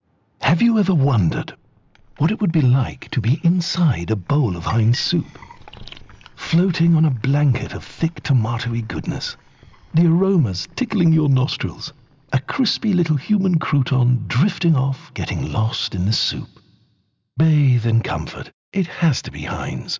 Heinz’s latest radio ad for its iconic Tomato Soup relies purely on sound to create a world rich in warmth, nostalgia, and sensory appeal.
Playful and slightly surreal, the ad invites listeners to imagine floating in a bowl of Heinz Tomato Soup, like a human crouton. Guided by a calm, reassuring voice, the spot builds a sensory world of warmth and familiarity without relying on visuals.
A simple idea, seasoned with wit, sharp writing and immersive sound design, Heinz reminds us that great storytelling doesn’t always need a screen, just a strong concept and an evocative script.